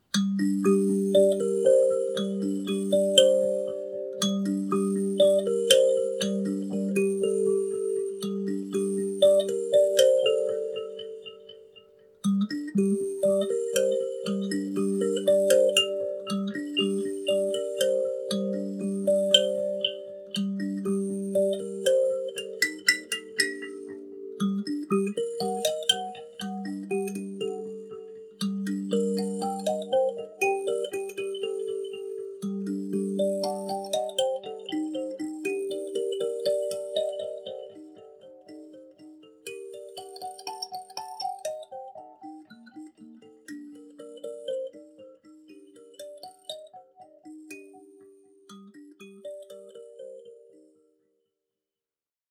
Sanza électro-acoustique
Des capteurs piezo et un jack 6.35 sur ce modèle permettent de le brancher à un ampli, d’utiliser des boites d’effets.
Dans la démo ci dessous, vous pouvez entendre la sanza electro enregistrée avec une boite d’effets, ( essentiellement un délai et une reverb )
electro-delai.mp3